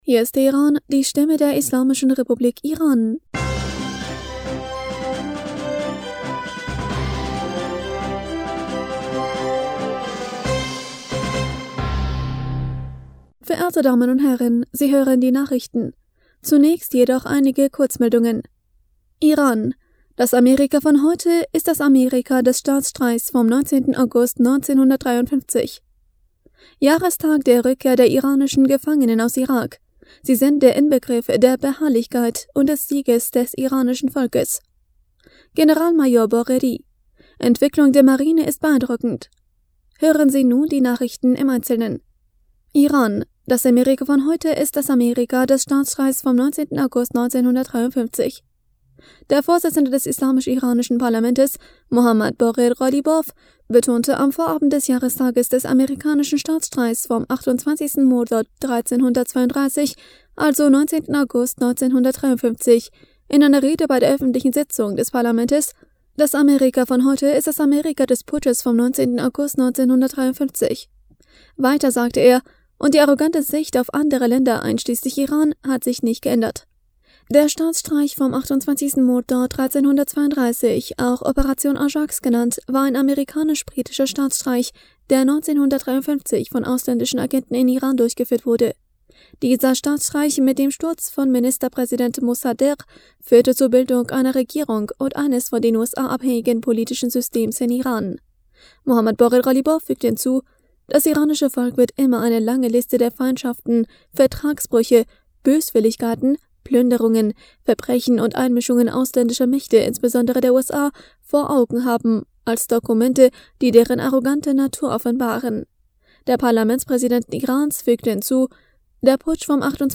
Nachrichten vom 17. August 2022